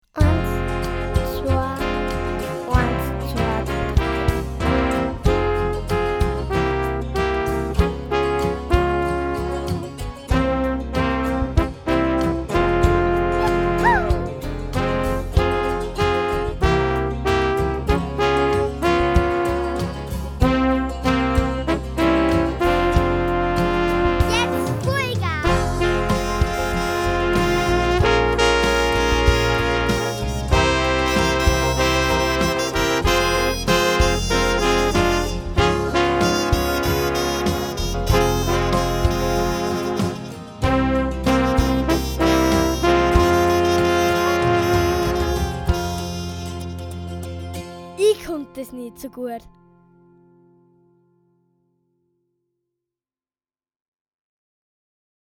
Besetzung: Trompete